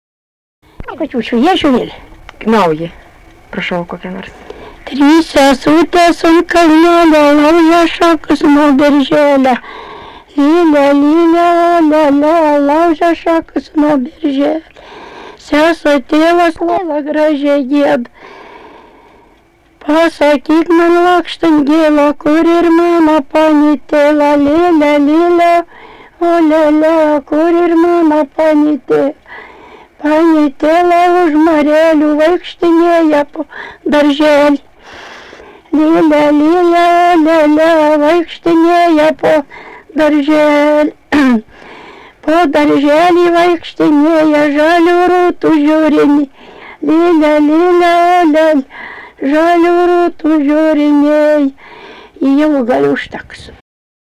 daina
Eiminiškiai
vokalinis